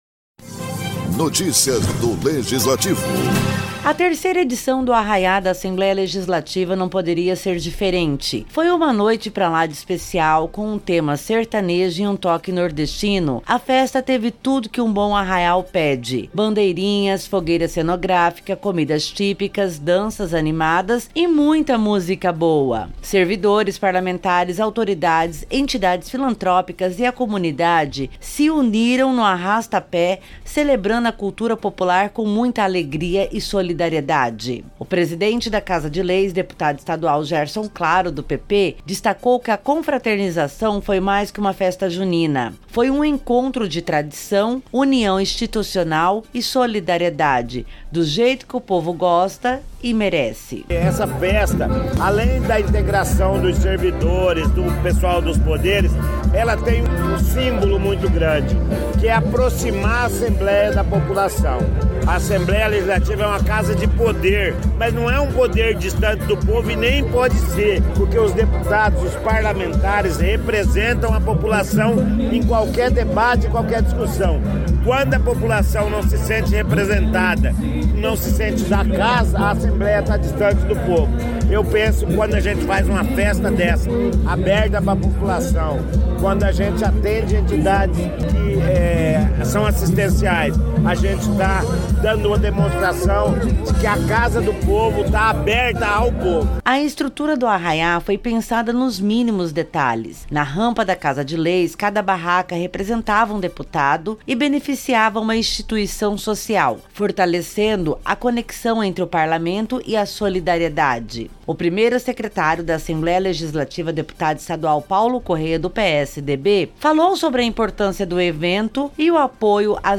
Arraiá da ALEMS agita o Parque dos Poderes com tradição e alegria
O 3º Arraiá da ALEMS reuniu servidores, deputados, autoridades e a população em uma noite especial de diversão e solidariedade. Com tema sertanejo e toque nordestino, a festa teve comidas típicas, música, danças, bandeirinhas e fogueira cenográfica.